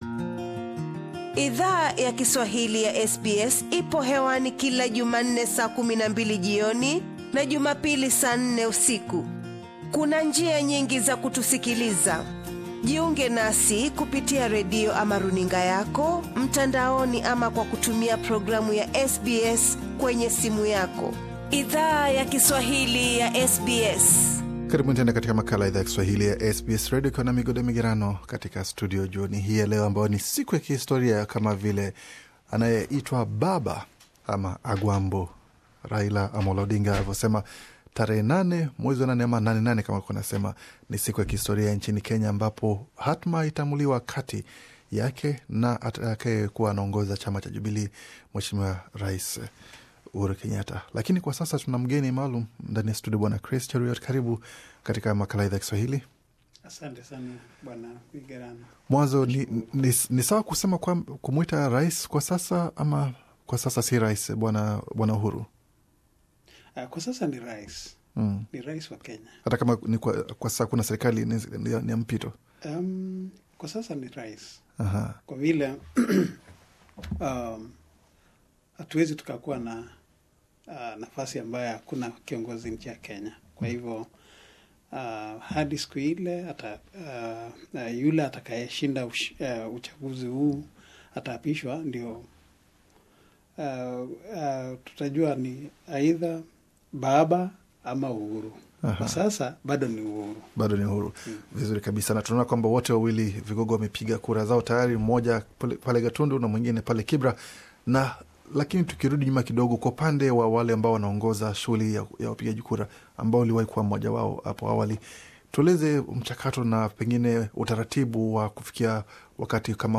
Wakenya wanapo anza kupiga kura, wasi wasi ume ongezeka kuhusu usalama wa kura wanazo piga. SBS Swahili ime zungumza na afisa wa zamani katika uchaguzi mkuu nchini Kenya, aliye zungumzia mchakato mzima wakupiga kura nchini humo.